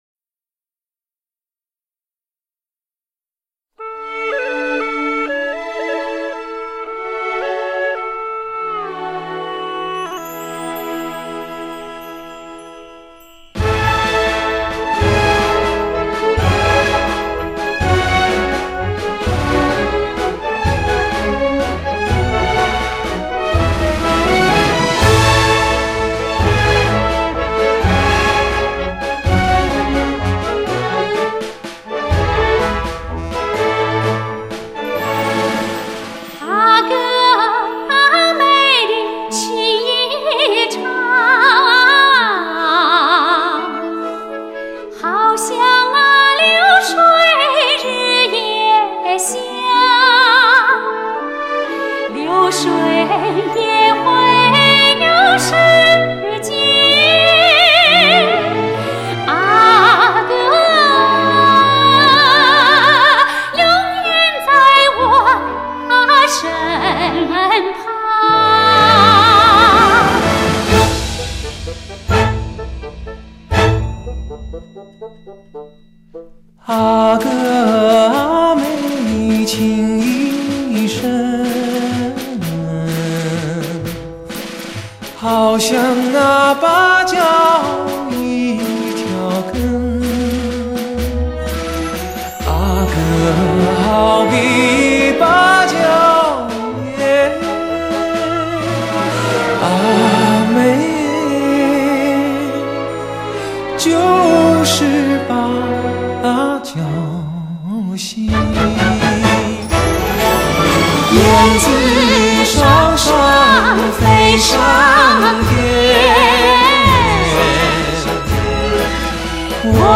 近乎完美的音色和无可挑剔的声音